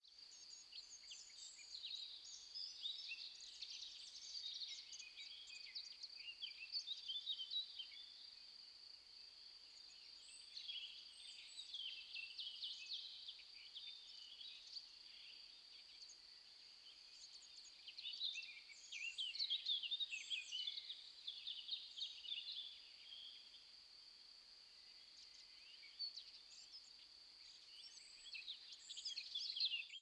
forest_ambiX.wav